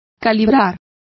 Complete with pronunciation of the translation of gaging.